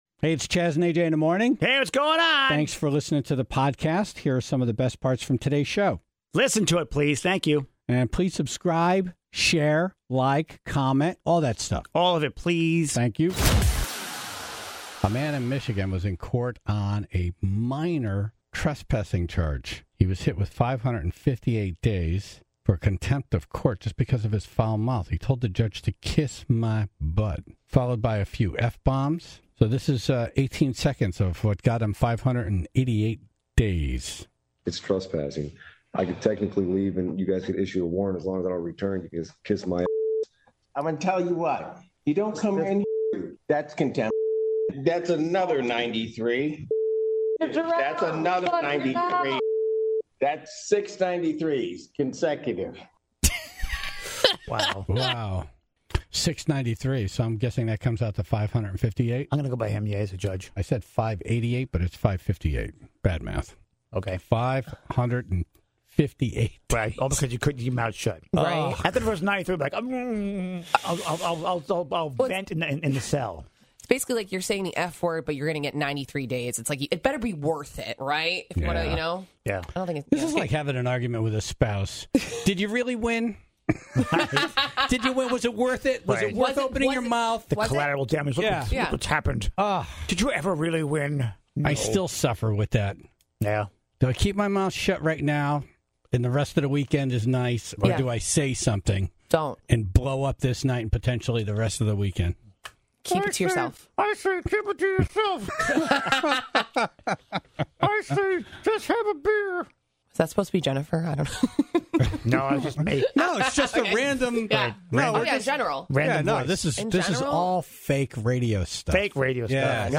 The show seemed split on whether or not this was justified, but the Tribe called in their stories of being kicked out of concerts.